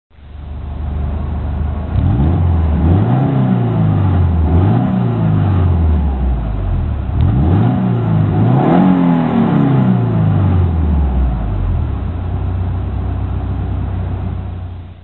音は車内から聞くとレガリスRとほとんど同じ感じで正直ちょっと拍子抜け(^^;
音(その1・車内での空ぶかし　mp3形式・74kB)